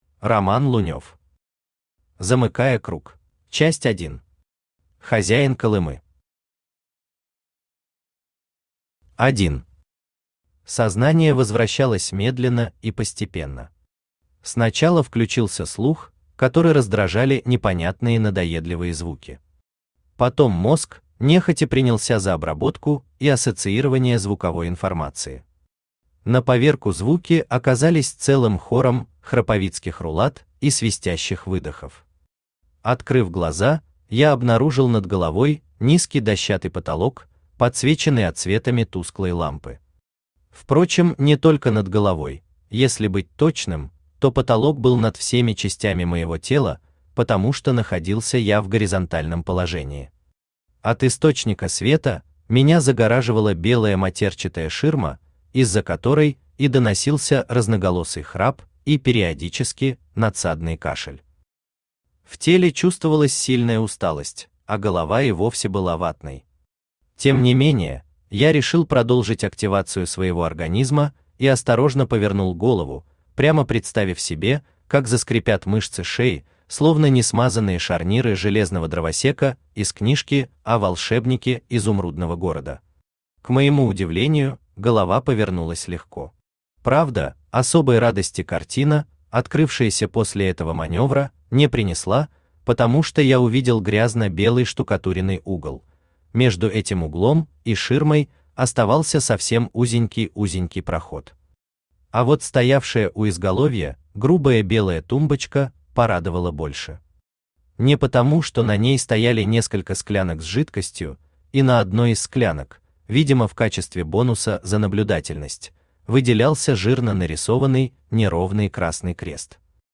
Aудиокнига Замыкая круг Автор Роман Константинович Лунев Читает аудиокнигу Авточтец ЛитРес.